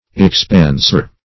Expansure \Ex*pan"sure\ (?shur; 135)